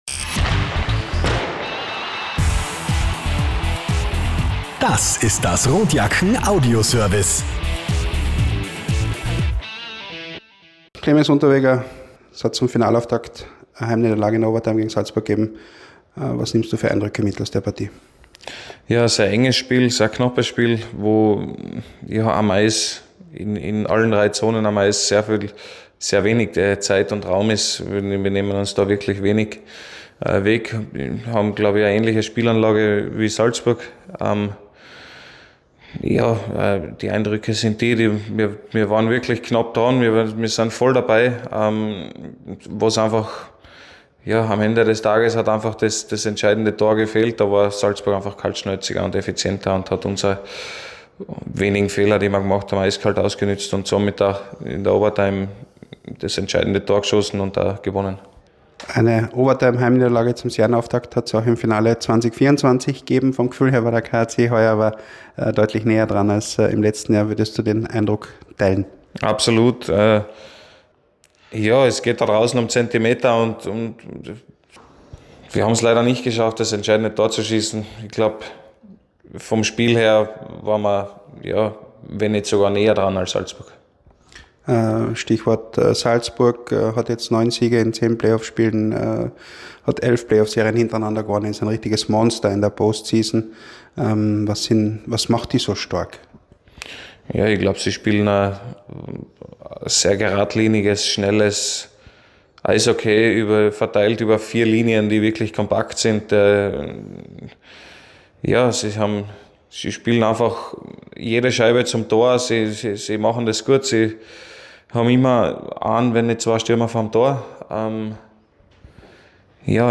Pre-Game-Kommentar: